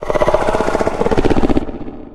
sounds / monsters / lurker / idle_0.ogg